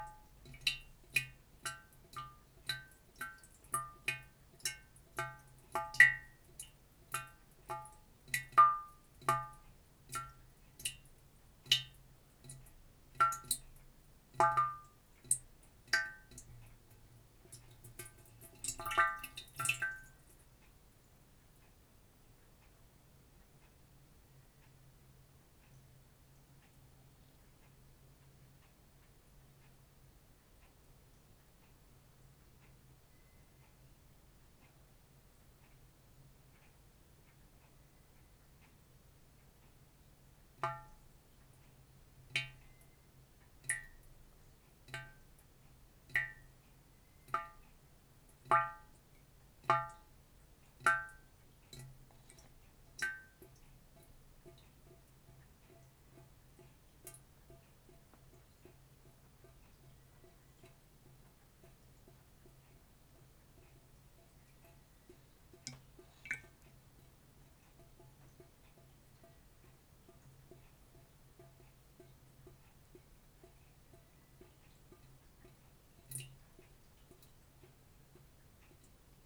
Here's brandnew favorite "Desk top Suikinkutsu" Enjoy Suikinkutsu Sounds!